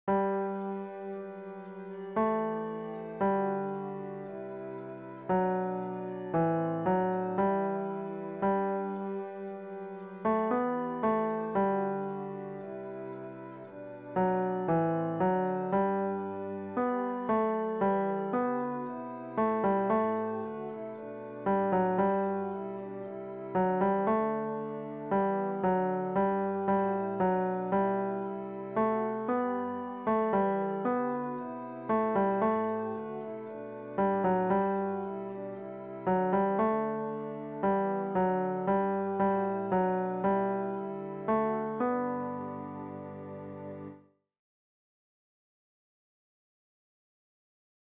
Chorproben MIDI-Files 469 midi files